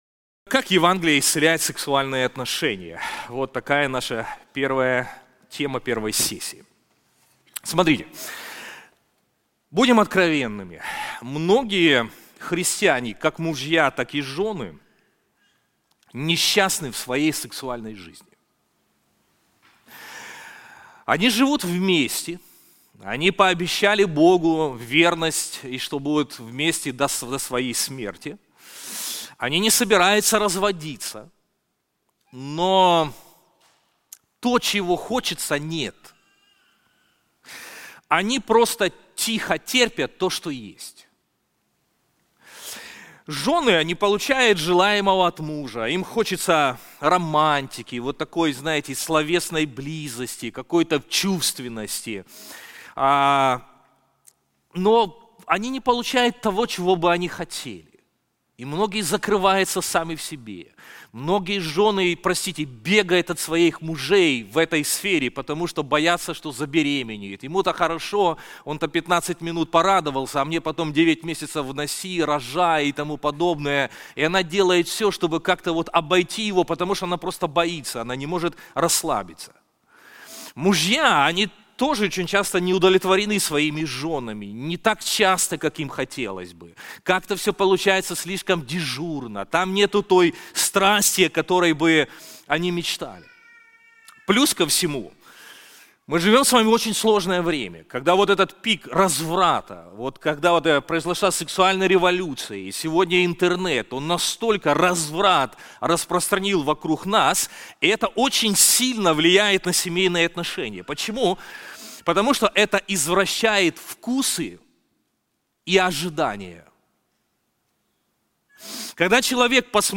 Конференции Господство Христа в сексуальных отношениях